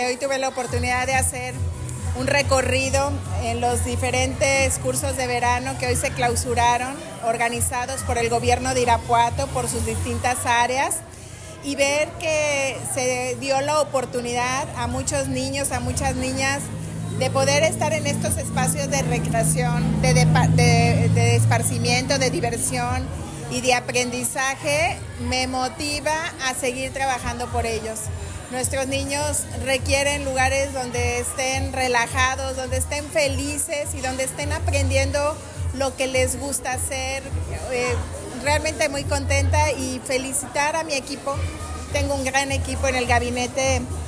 AudioBoletines
Lorena Alfaro García, presidneta municipal